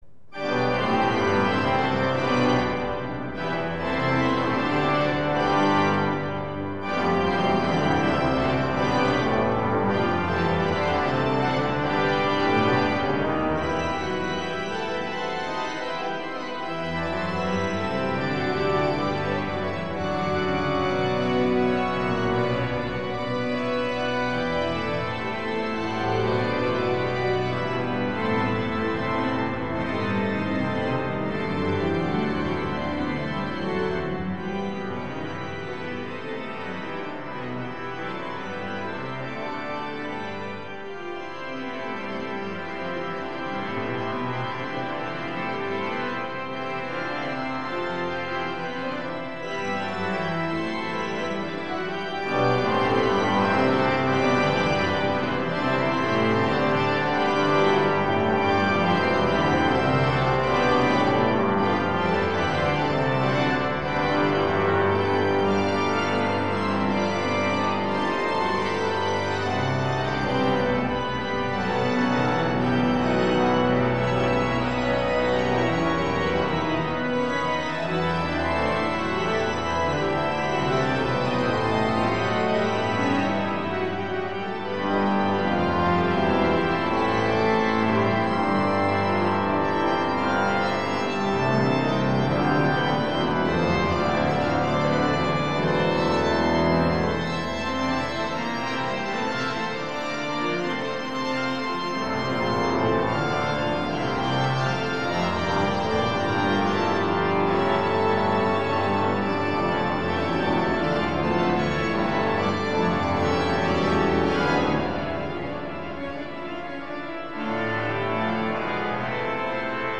C’est de l’orgue, et il contient :
paraphrase sur l’hymne Laudate Dominum